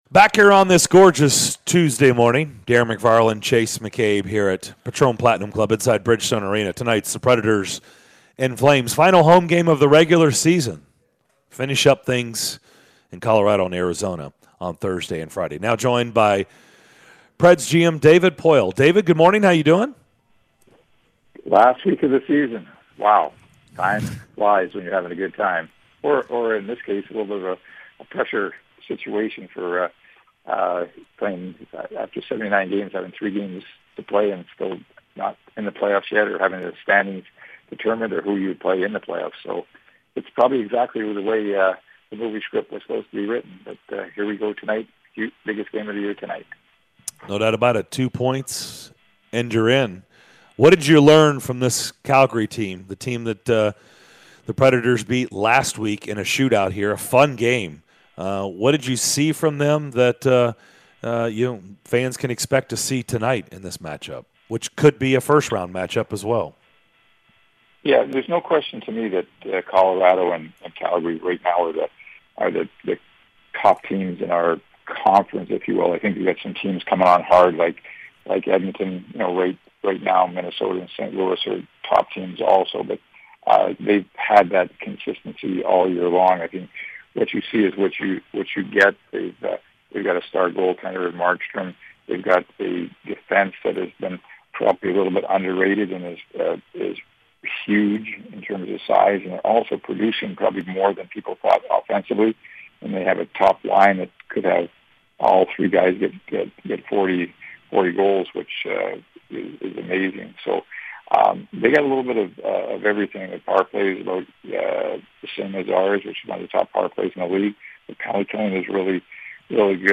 Nashville Predators GM David Poile joined the show to discuss his team's push to the playoffs during the final week of the regular season!